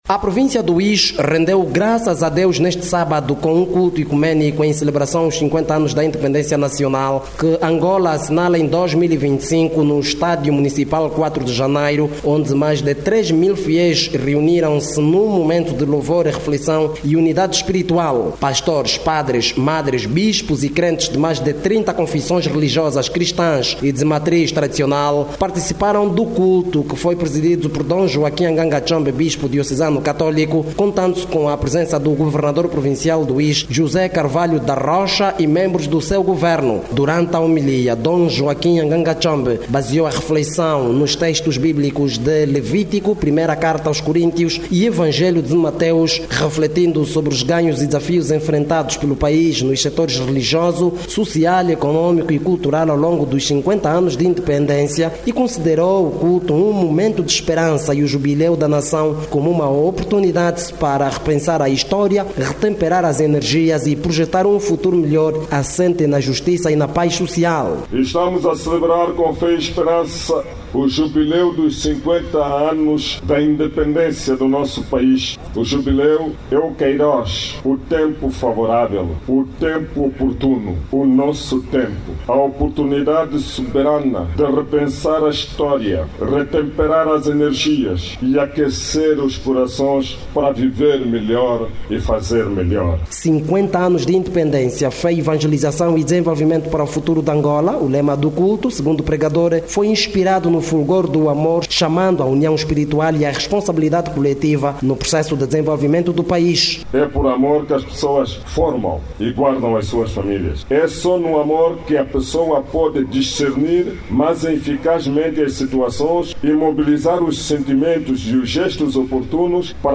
Mais de trinta congregações cristãs participaram do momento de oração no estádio quatro de Janeiro.